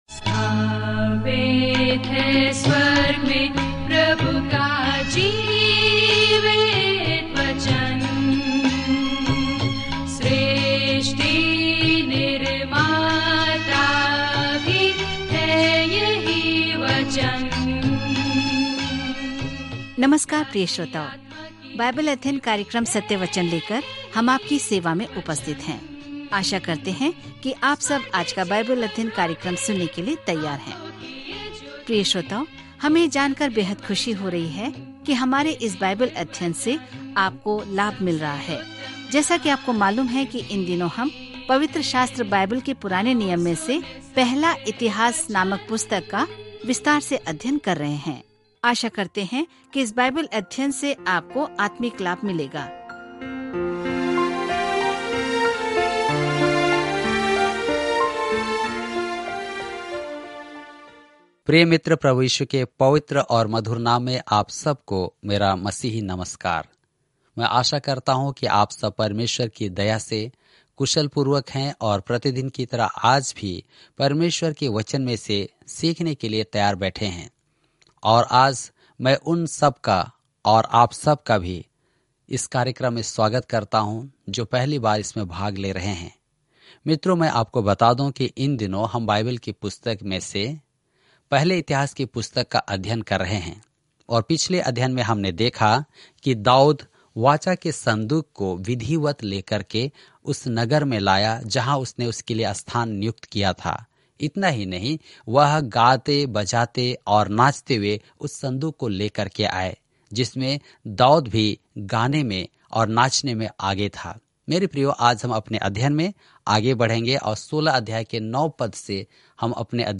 पवित्र शास्त्र 1 इतिहास 16:9-43 1 इतिहास 17 दिन 5 यह योजना प्रारंभ कीजिए दिन 7 इस योजना के बारें में इतिहास कैद से लौटने वाले परमेश्वर के लोगों को यह याद दिलाने के लिए लिखा गया था कि वह उनके इतिहास के माध्यम से उनके लिए कितना महान रहा है। 1 क्रॉनिकल्स के माध्यम से दैनिक यात्रा करें जैसे कि आप ऑडियो अध्ययन सुनते हैं और भगवान के वचन से चुनिंदा छंद पढ़ते हैं।